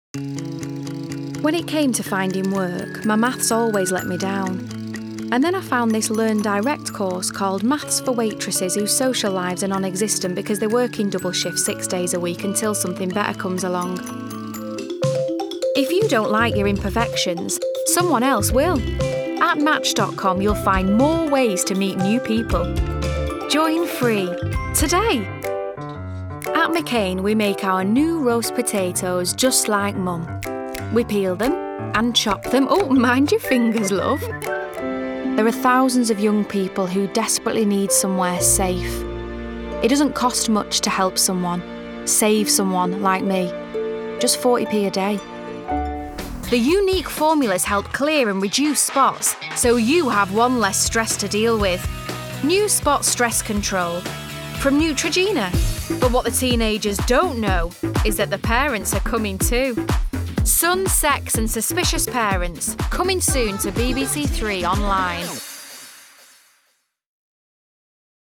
Voice Reel
Commercial Reel